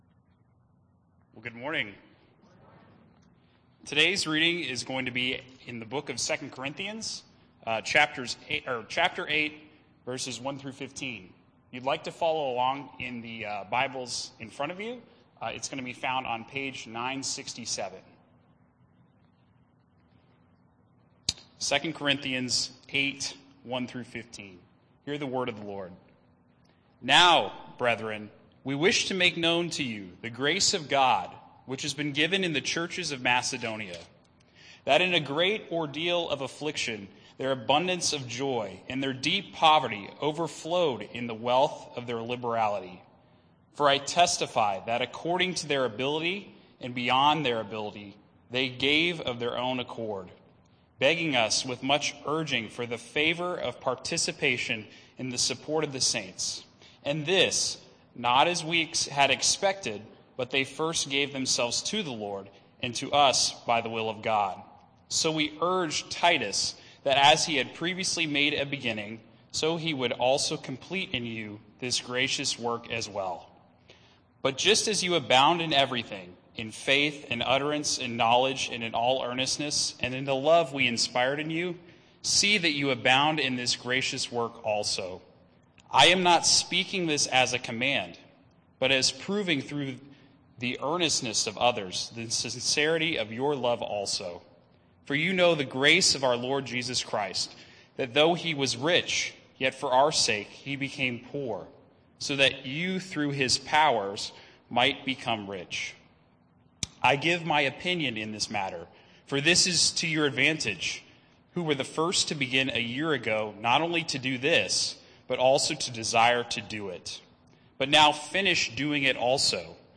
Worship Service December 5, 2021